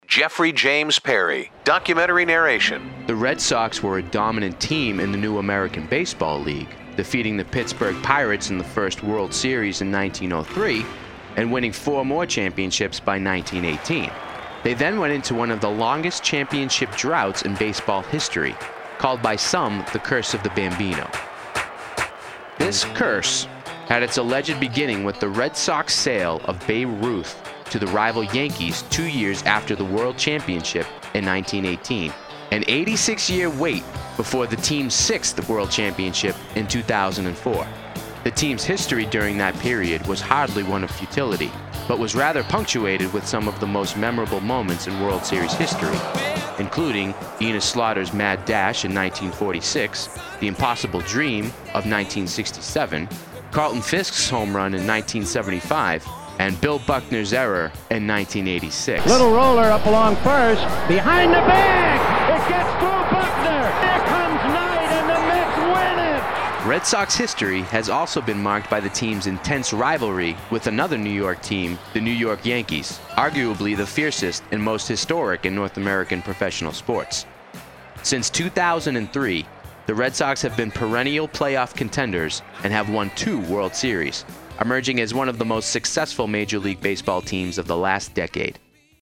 Female Narration